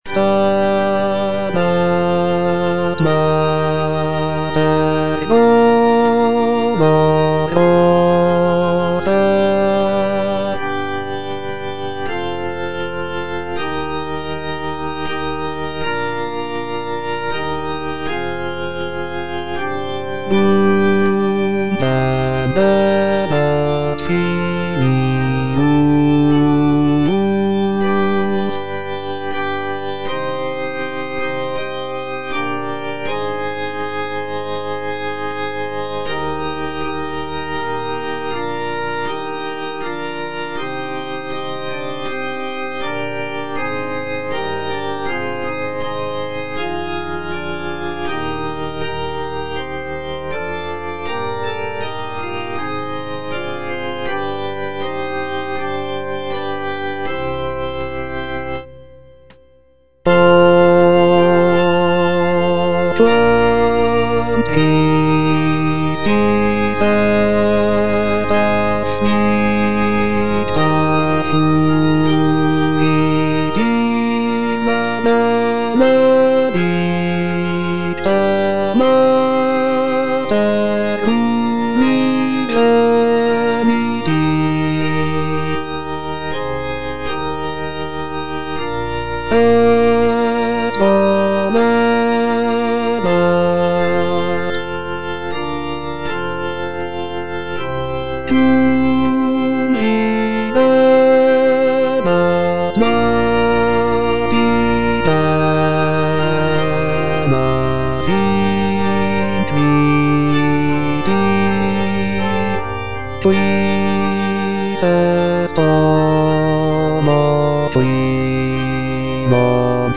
G.P. DA PALESTRINA - STABAT MATER Stabat Mater dolorosa (bass I) (Voice with metronome) Ads stop: auto-stop
sacred choral work